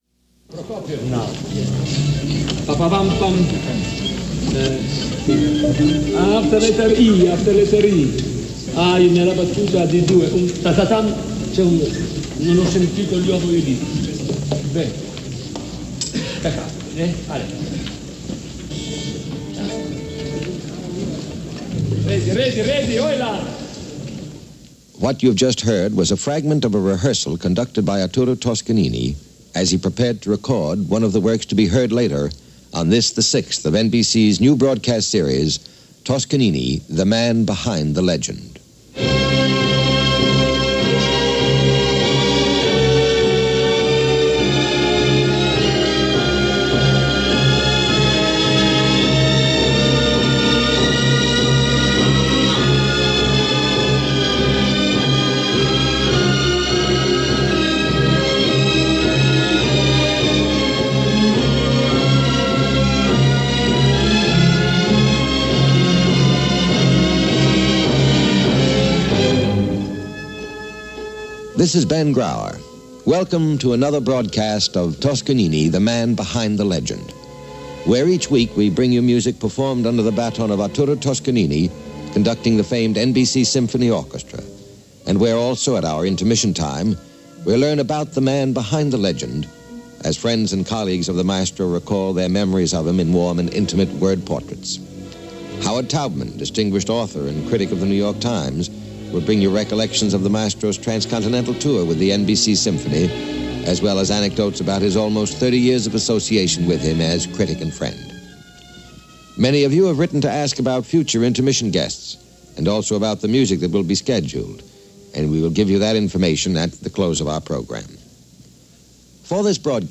I was originally broadcast on July 10, 1963 and features an interview with Journalist and Biographer Howard Taubman , who was a reporter during the very early days of Toscanini’s tenure with the NBC Symphony, as well as his earlier post as Music Director or the New York Philharmonic .
The musical portions, though not indicated if they are from concert broadcasts or commercials lps (I suspect lp’s, but since NBC was owned by RCA at the time, anything was possible) are Mozart’s Symphony Number 40 and Prokofiev’s Symphony number 1 “Classical”.